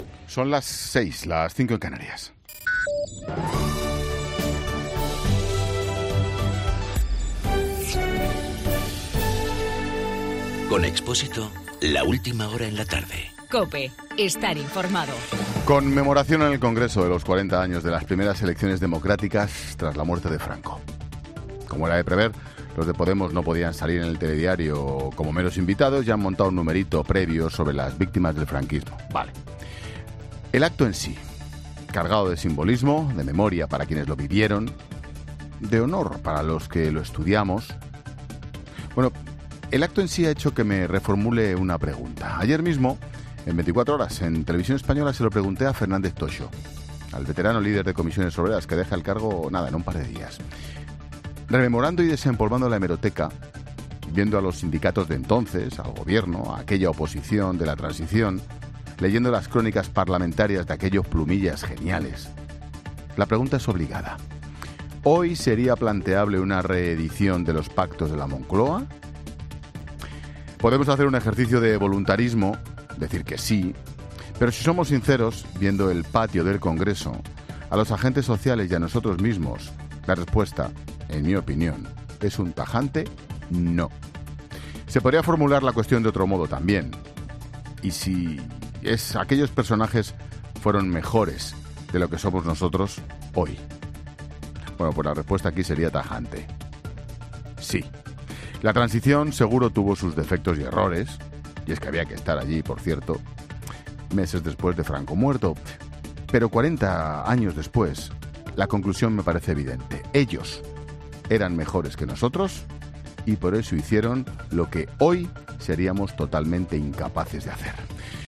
AUDIO: Monólogo de Ángel Expósito a las 18h. analizando la conmemoración de los 40 años de las primeras elecciones democráticas